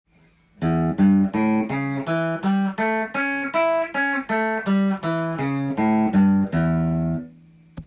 横移動７thコード
５度音　６弦始まり
１つの弦につき２音ずつ弾く横移動の練習もしておくと